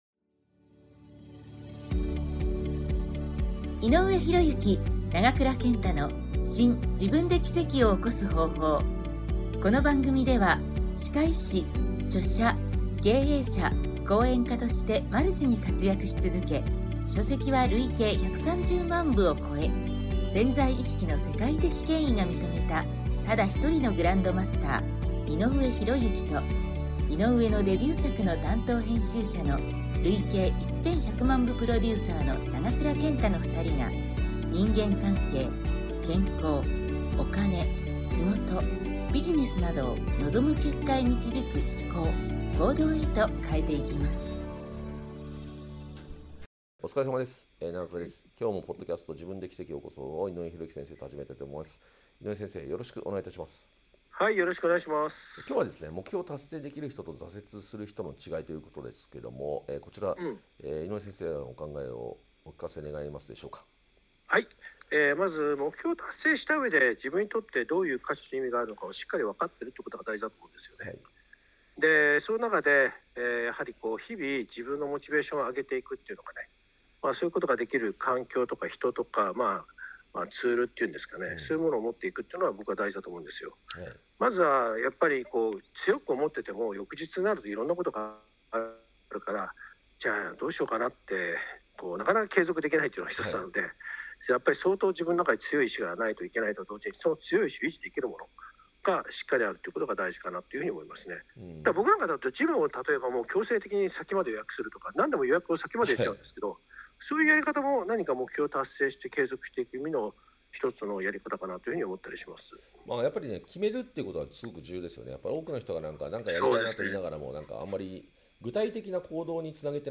2人による今回のPodcast第273弾は 『目標を「達成できる人」と「挫折する人」の違い』について についてお伝えします。